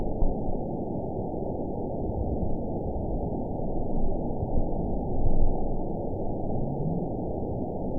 event 920812 date 04/09/24 time 23:40:23 GMT (1 year, 1 month ago) score 9.42 location TSS-AB03 detected by nrw target species NRW annotations +NRW Spectrogram: Frequency (kHz) vs. Time (s) audio not available .wav